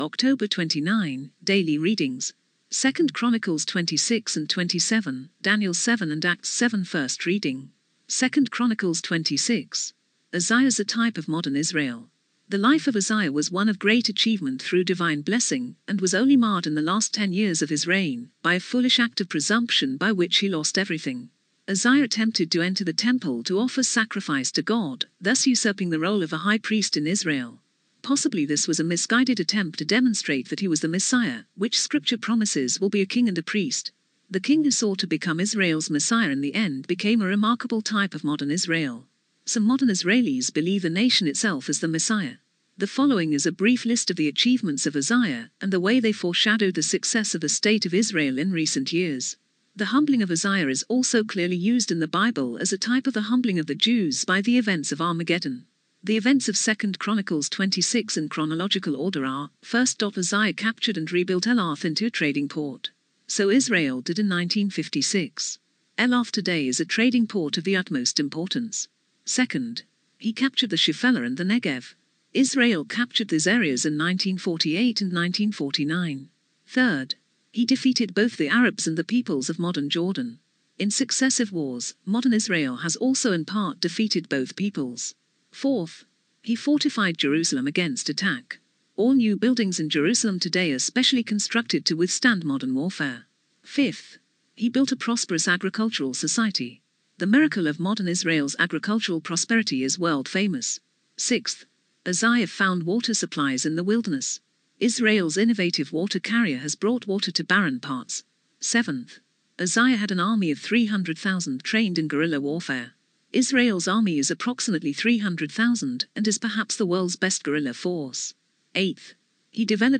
Audio Reading 1 - 2Ch 26